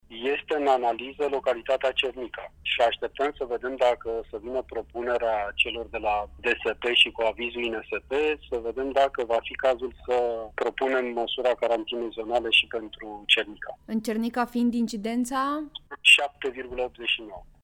Prefectul județului, Daniel Zamfir, spune că nu se pot lua alte măsuri, mai aspre decât carantinarea zonelor în care incidența este peste 6:
De miercuri seară, în carantină ar putea intra și Cernica, mai spune prefectul: